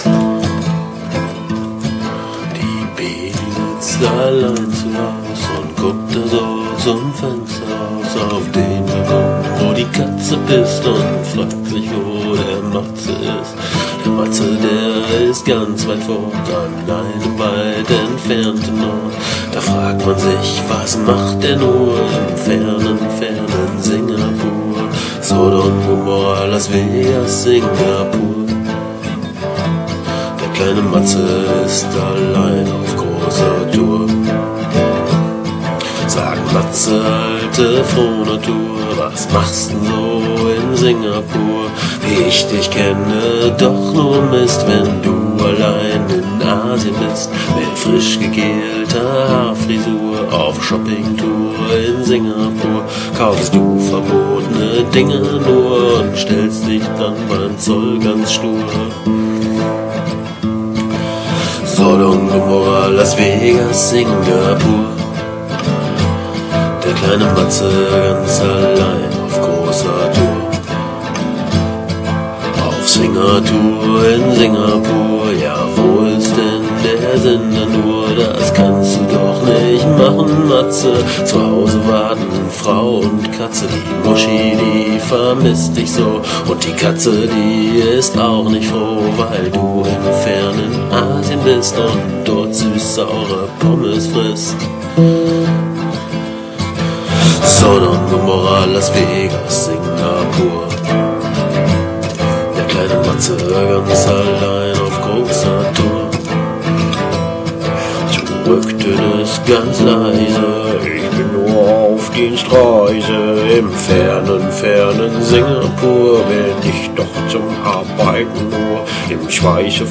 Ein lustiges kleines Liedchen aus mittelalten Tagen. Denkbar einfach eingespielt, ein Sagenwirmal-Mikrophon (Handy), Akustikgitarre und Gesang, ein Take, fertig. Dazu noch leicht verschnupft, egal, passt schon.